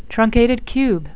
(trun-cat-ed   cube)